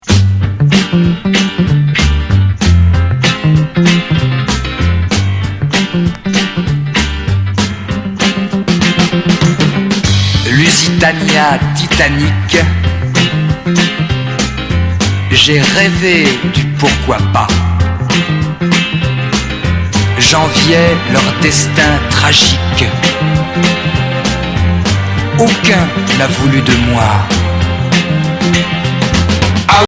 Chanteur 60's